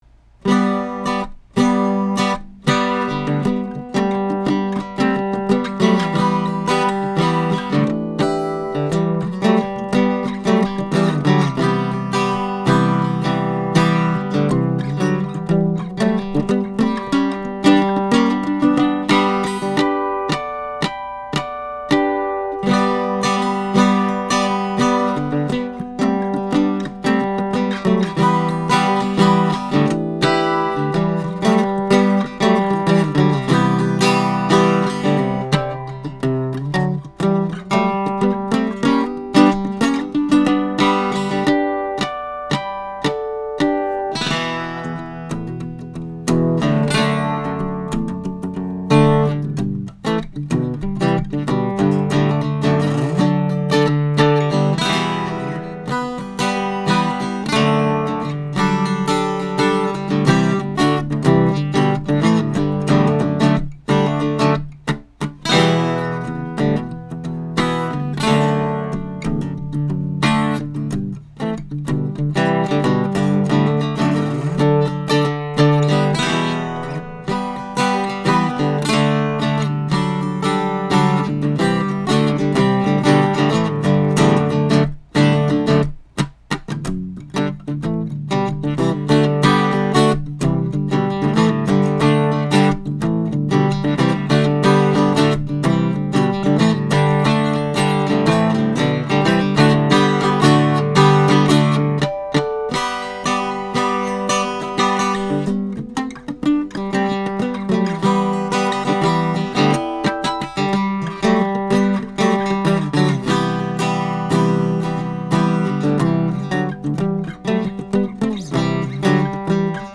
RN17 going into a Trident 88 console to Metric Halo ULN-8 converters:
1938 HARMONY ROYAL CREST ARCHTOP